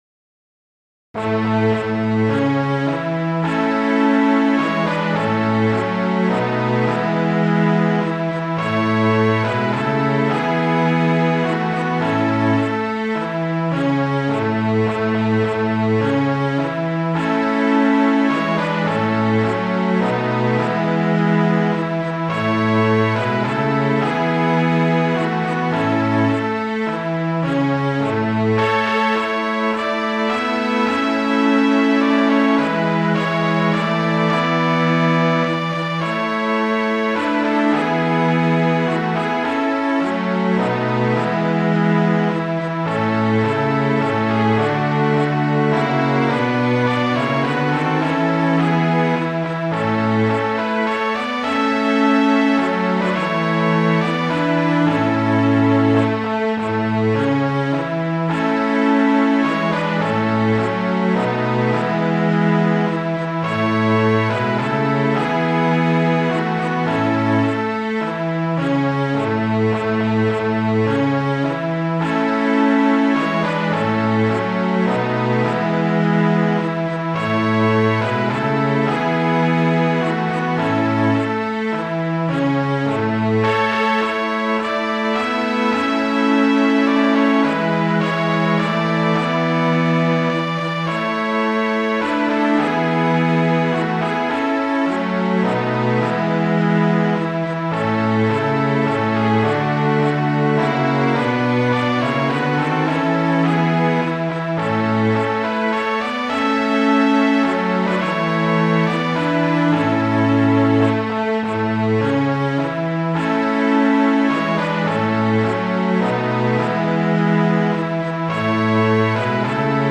Midi File, Lyrics and Information to Star Spangled Banner